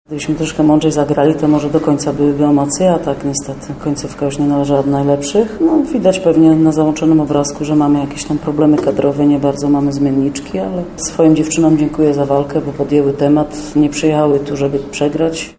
Oto co powiedziały bohaterki meczu podczas konferencji prasowej po jego zakończeniu: